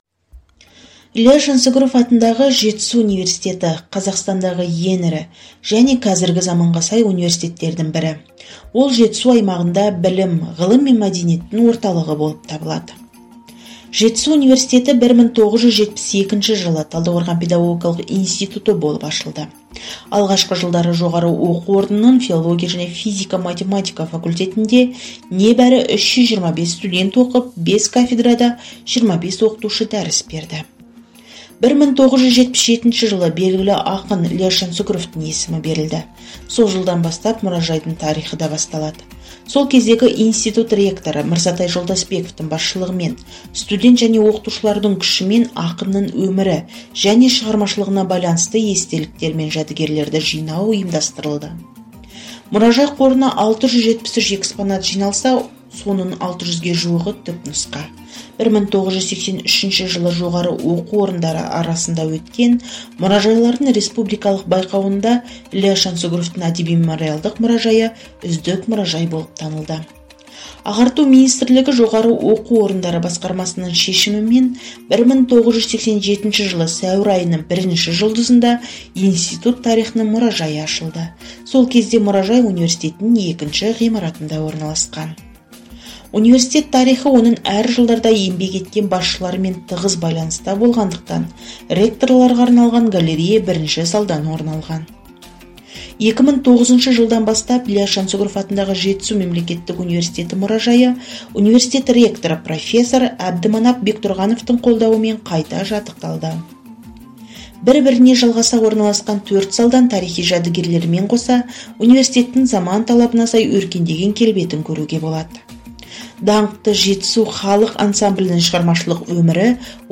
Озвучка-на-каз-с-музыкой.mp3